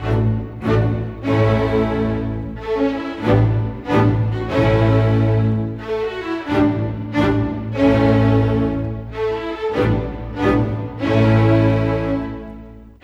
Rock-Pop 06 Strings 02.wav